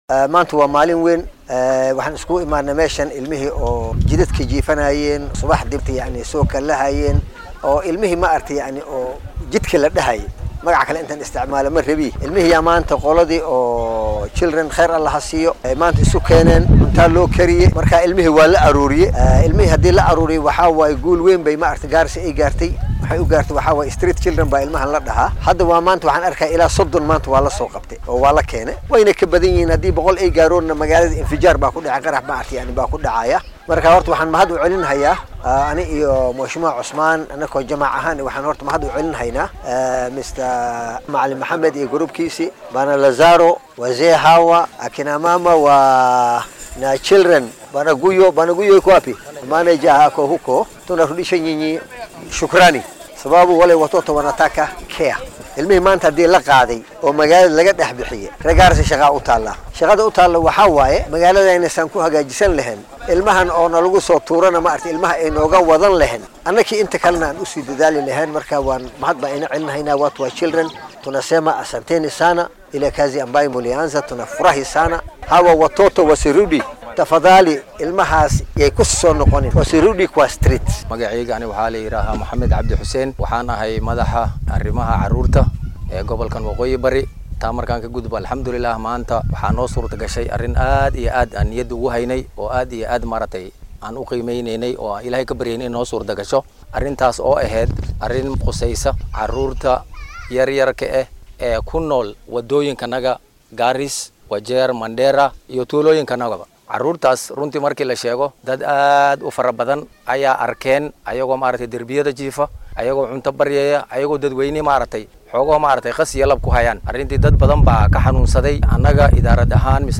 Waxaa magaalada Garissa lagu qabtay kulan looga arrinsanayay carruurta derbi jiifka ah iyagoona hal meel ah la isugu keenay carruurtan. Qaar ka mid ah dadkii soo qaban qaabiyay shirkaasi oo warbaahinta la hadlay ayaa yiri.